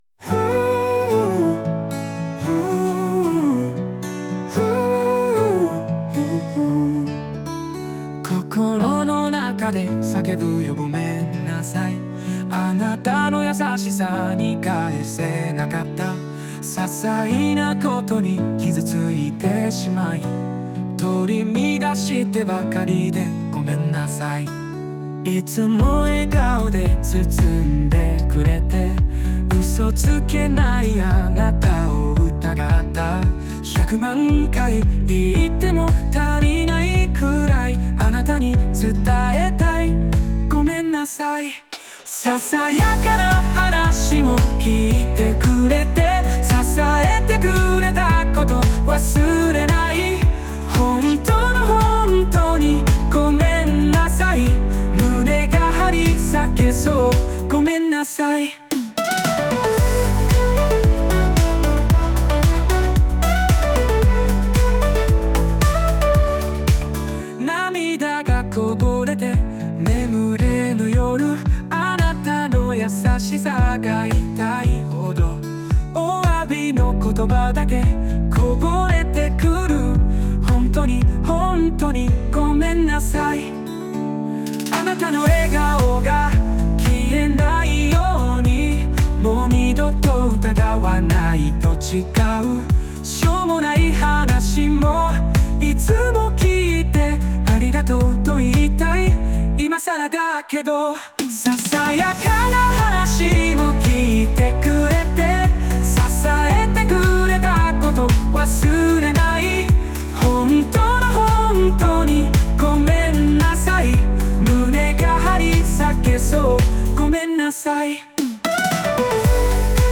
この曲はSunoAIで生成しております。
ballad heartfelt pop